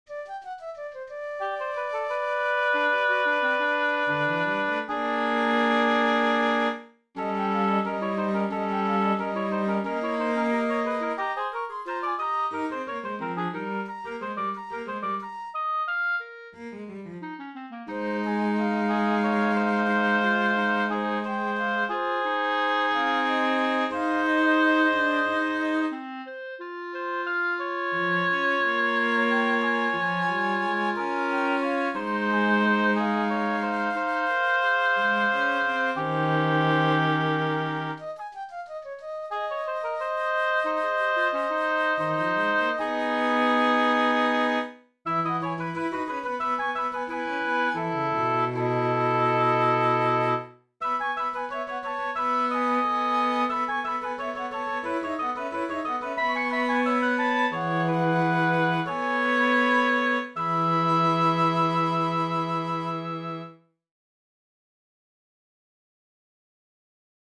no name" - pro flétnu, hoboj, klarinet a cello (to se taťka zase vyřádí při vymýšlení názvu ... ) /ukázku hraje automat, bez doplněné dynamiky, tempových změn apod./